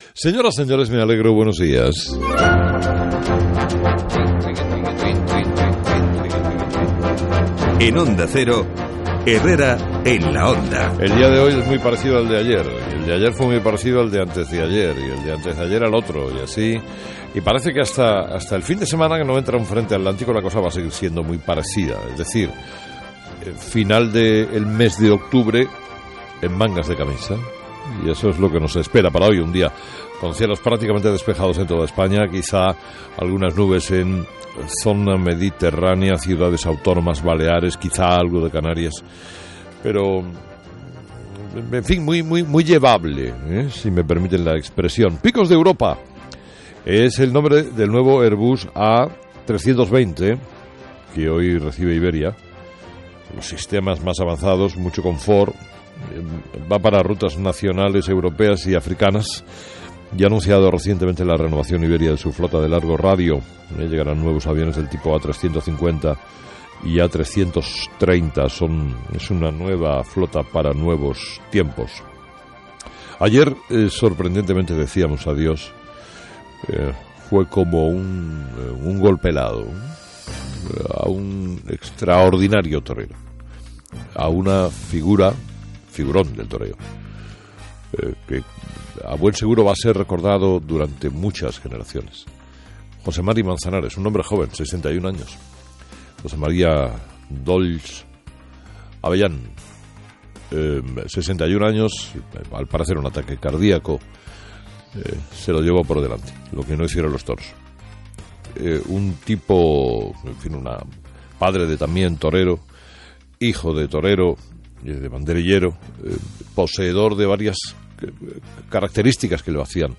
Carlos Herrera comenta en su editorial el "hecho notable" que se produjo el martes, después de que el presidente del Gobierno pidiera perdón ante los nuevos casos de corrupción de la Operación Púnica.